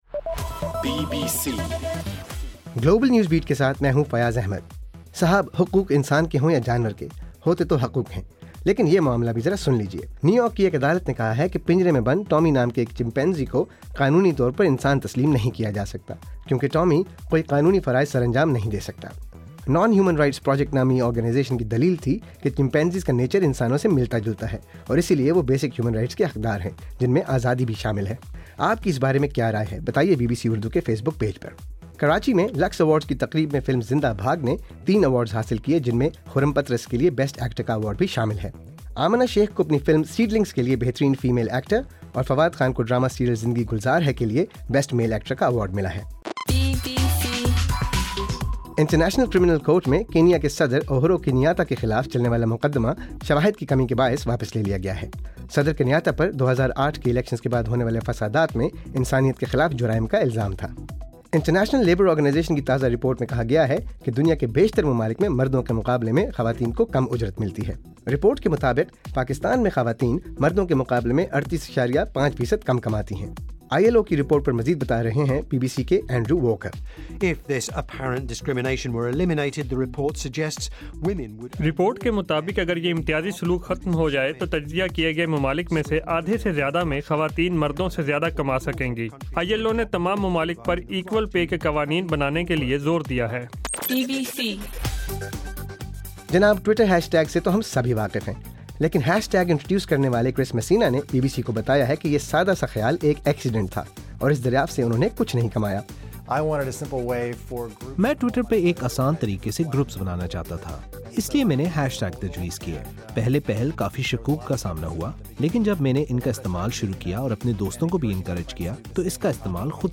دسمبر 5: رات 11 بجے کا گلوبل نیوز بیٹ بُلیٹن